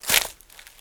STEPS Leaves, Walk 30.wav